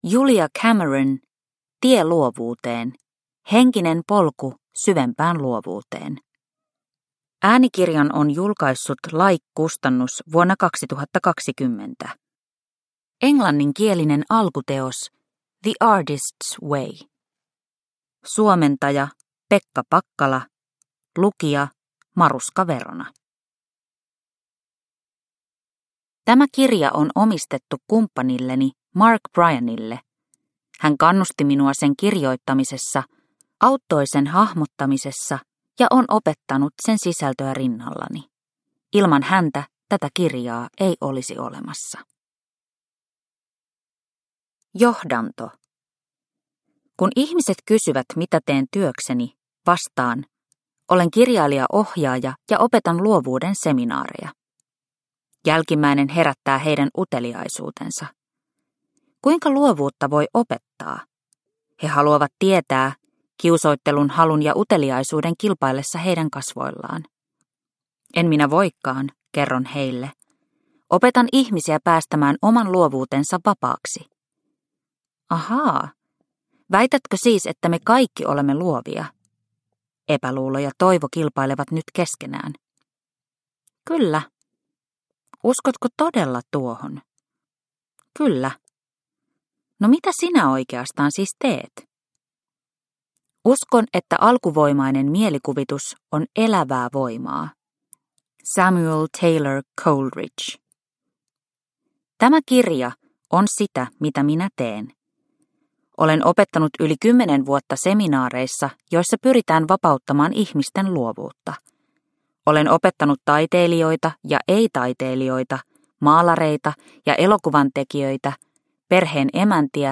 Tie luovuuteen – Ljudbok – Laddas ner